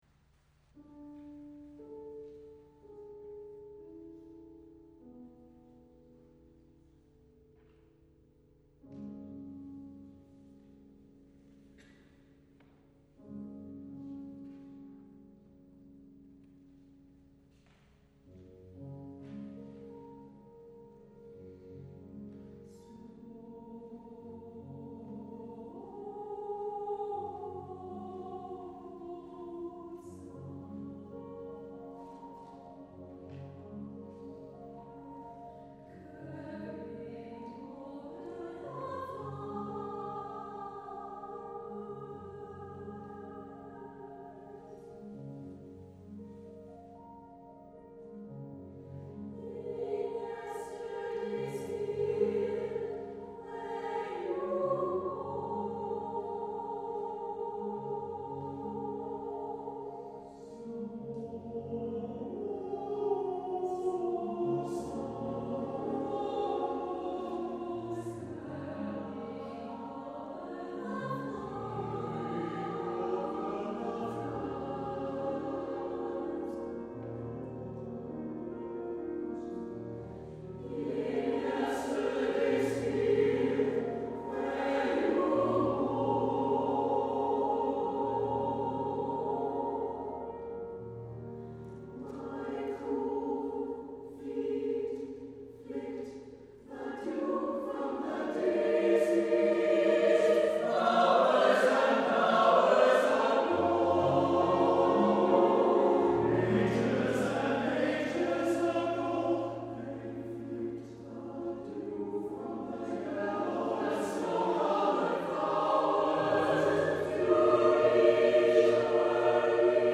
for SATB Chorus and Piano (1998)